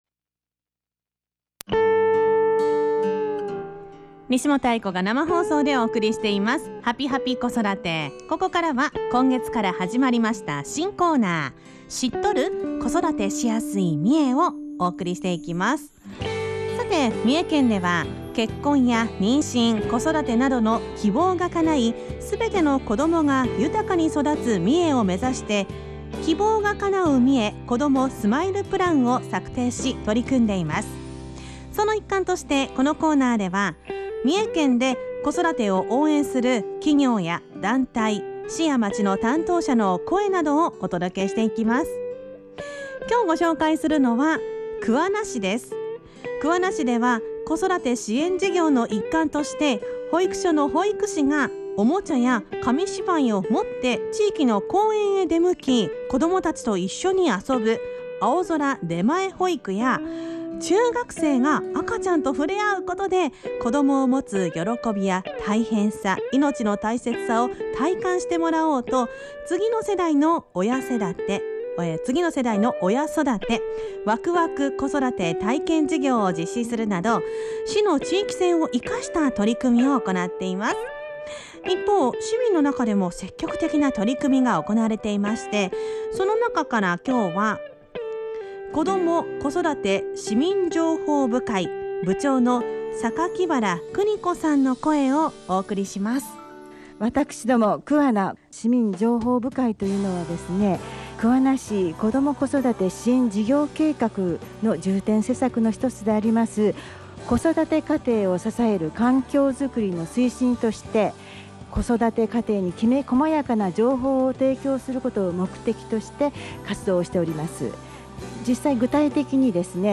インタビュー
県内各市町の子育てを応援している団体などを取材し、地域で活躍されている方の生の声をFM三重「はぴぱぴ子育て」「EVENING COASTER」内で放送しました。